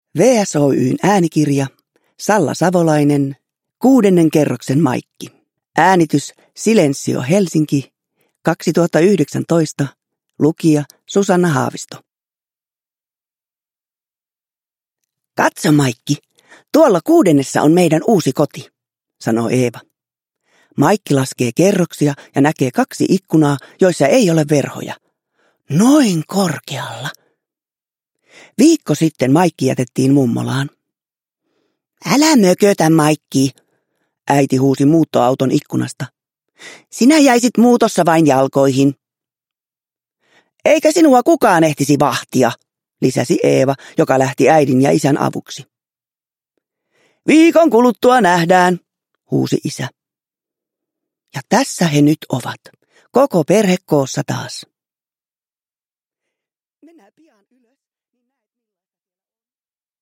Kuudennen kerroksen Maikki – Ljudbok – Laddas ner
Uppläsare: Susanna Haavisto